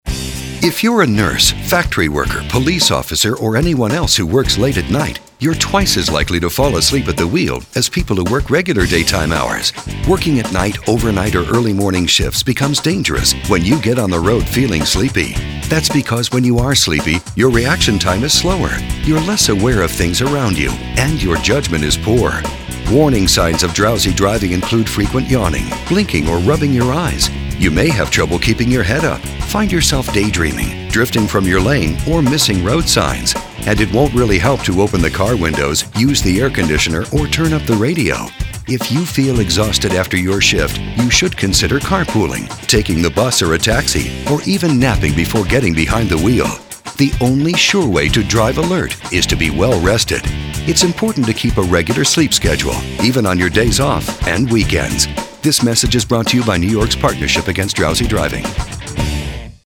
Shift Workers :60 Radio PSA.